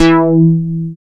75.03 BASS.wav